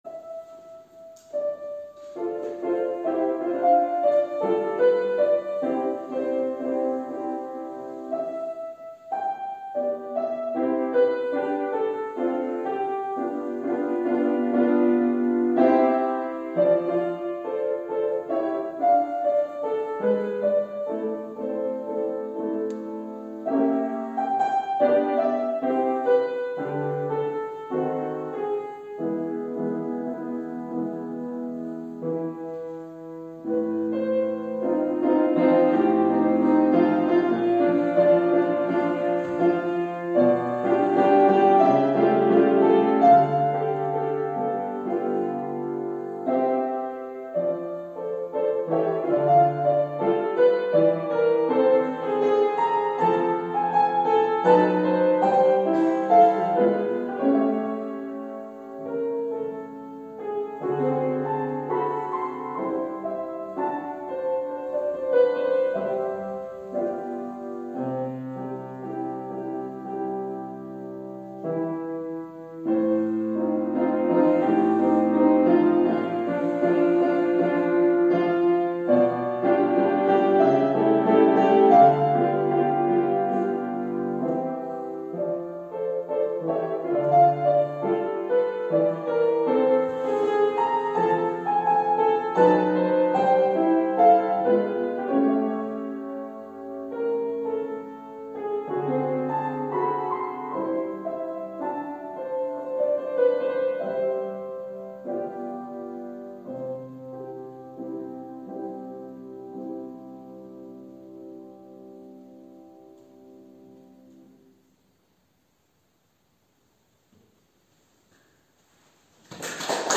Orgelvorspiel